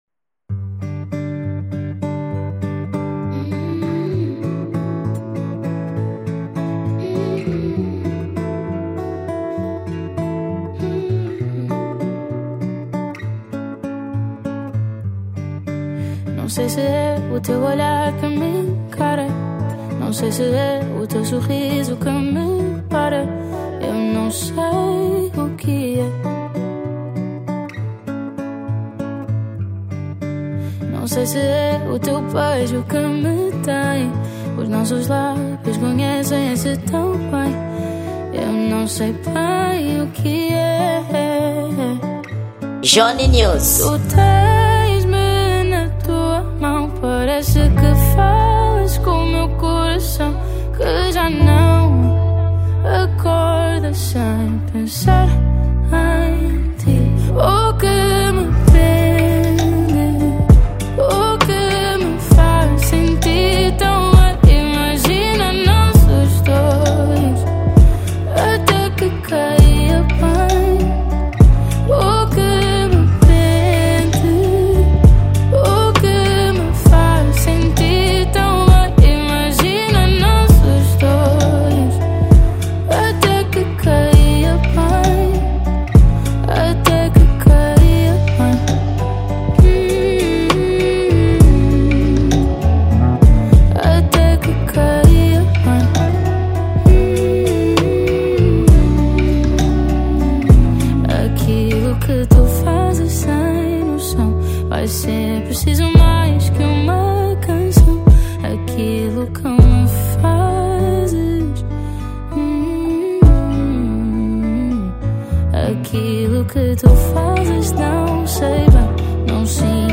Gênero: Afro Pop